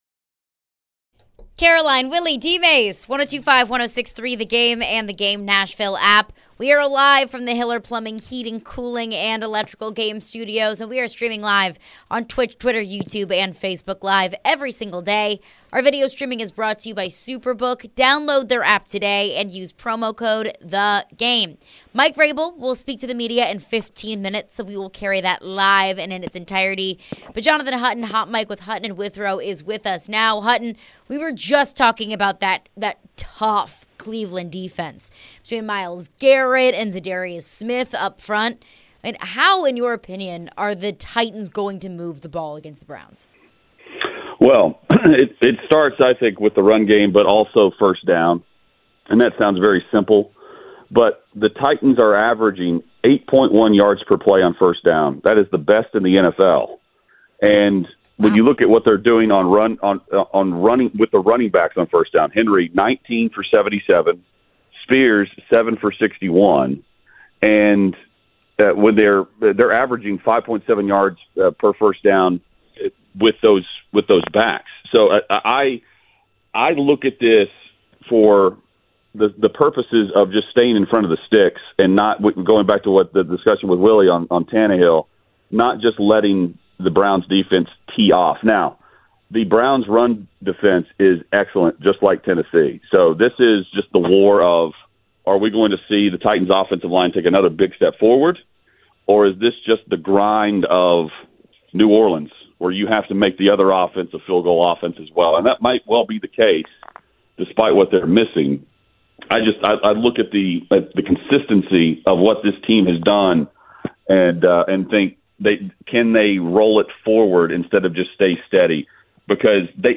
Mike Vrabel Press Conference, Reactions
Then, Titans Head Coach Mike Vrabel steps to the podium and we react to all of the updates.